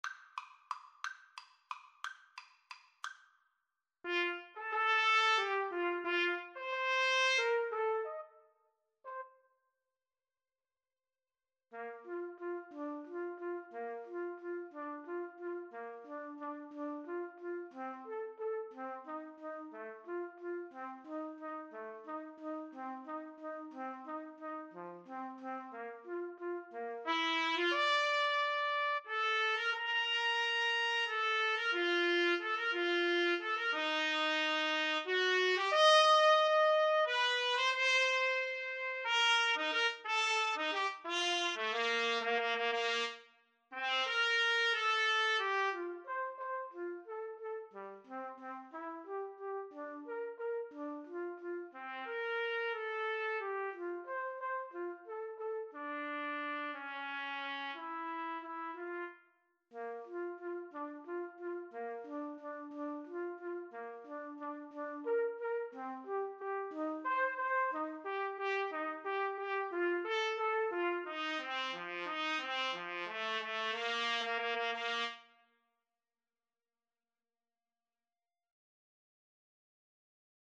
~ = 180 Tempo di Valse
3/4 (View more 3/4 Music)
Classical (View more Classical Trumpet Duet Music)